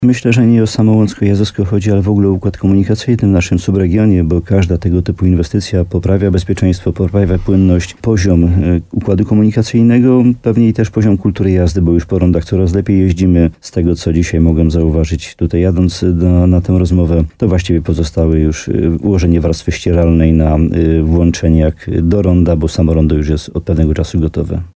– To też poprawa bezpieczeństwa – mówił Jan Dziedzina w programie Słowo za Słowo na antenie radia RDN Nowy Sącz.
Rozmowa z Janem Dziedziną: Tagi: Jan Dziedzina rondo HOT Zarząd Dróg Wojewódzkich w Krakowie droga wojewódzka 969 budowa ronda Nowy Sącz Jazowsko Słowo za Słowo gmina Łącko